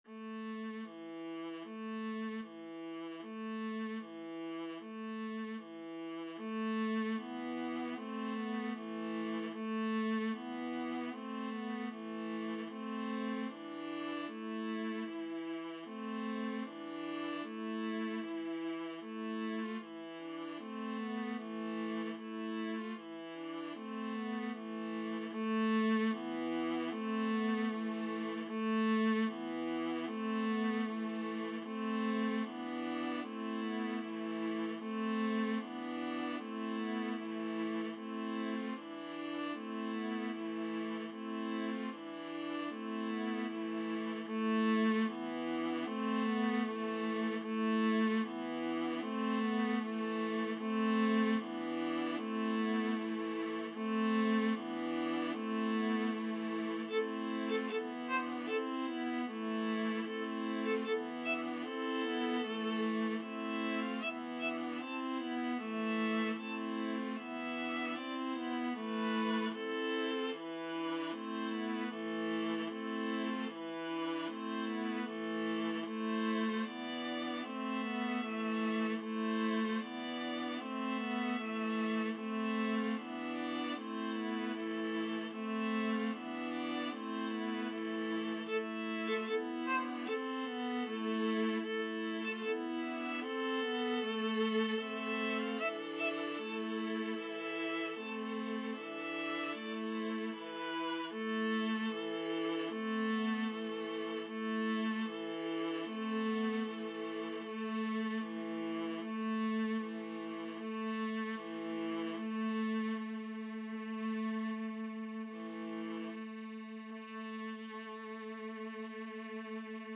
Viola 1Viola 2Viola 3Viola 4
=76 Feierlich und gemessen, ohne zu schleppen ( = c.76)